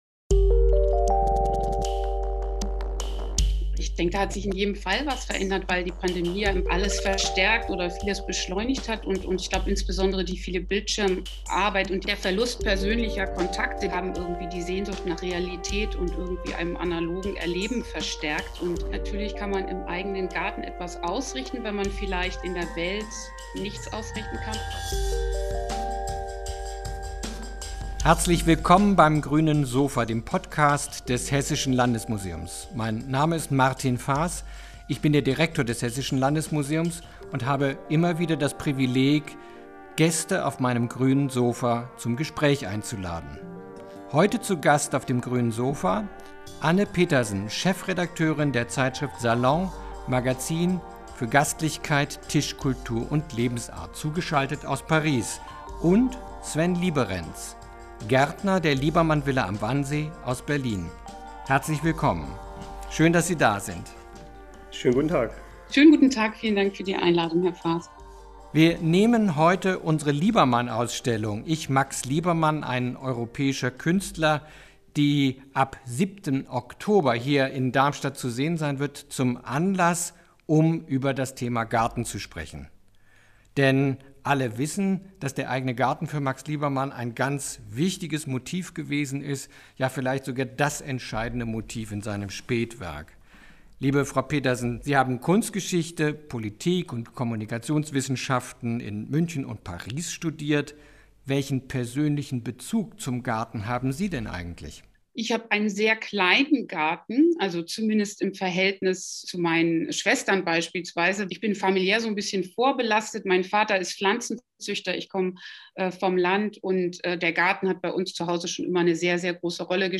die ansteigende Begeisterung rund um das Phänomen des Garten-Hypes im aktuellen Podcast-Interview des Hessischen Land...